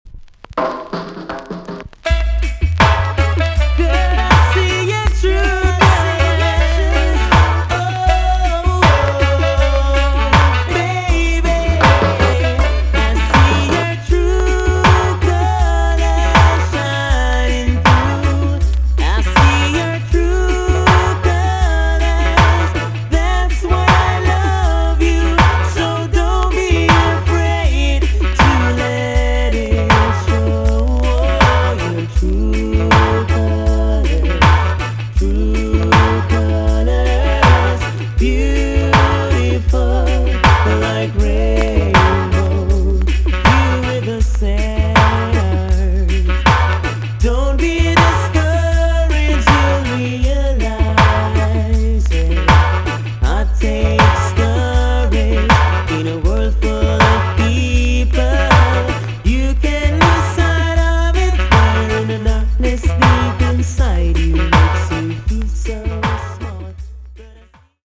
TOP >80'S 90'S DANCEHALL
B.SIDE Version
VG+ 少し軽いチリノイズが入ります。
HEAVY ROCK RIDDIM